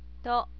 to -
to.wav